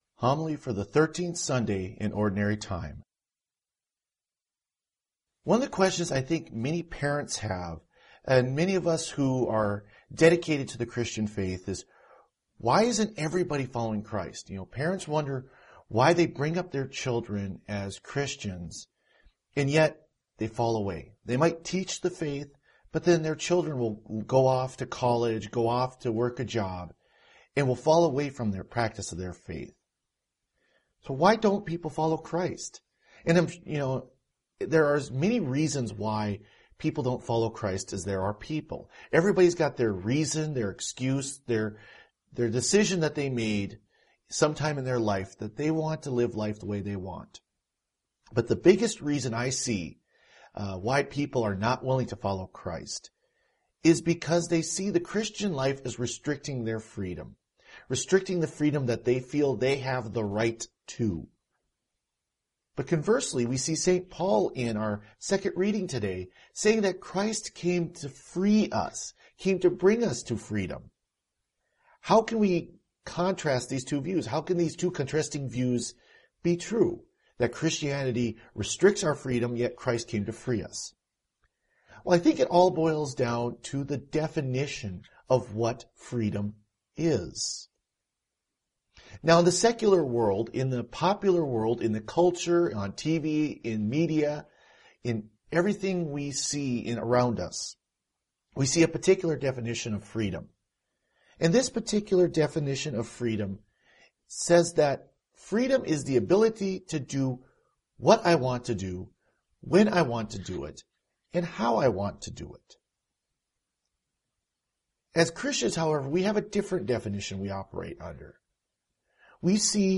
Homily for the Thirteenth Sunday in Ordinary Time